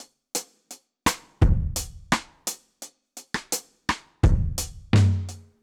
Index of /musicradar/dub-drums-samples/85bpm
Db_DrumsB_Dry_85-02.wav